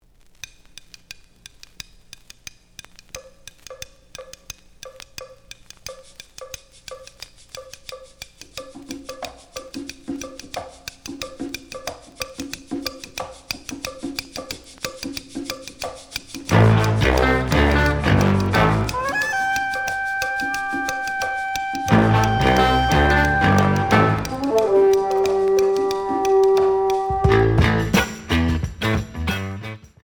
The audio sample is recorded from the actual item.
●Format: 7 inch
●Genre: Funk, 60's Funk